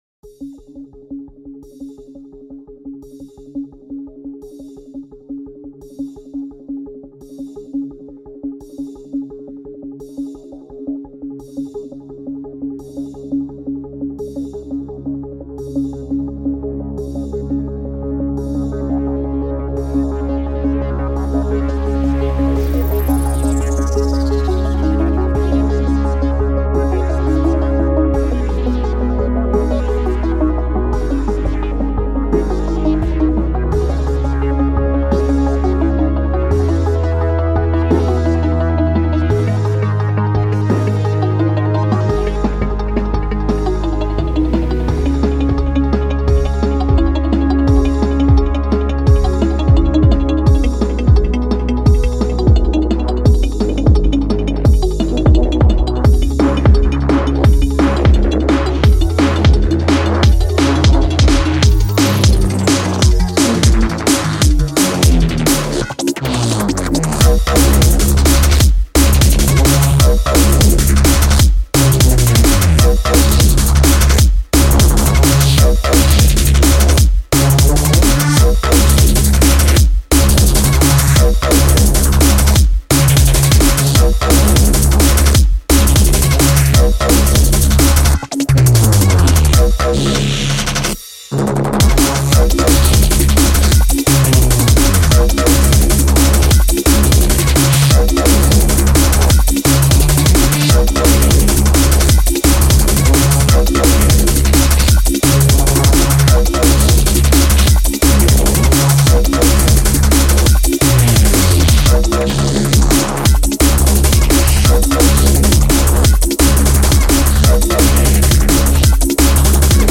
genre:dnb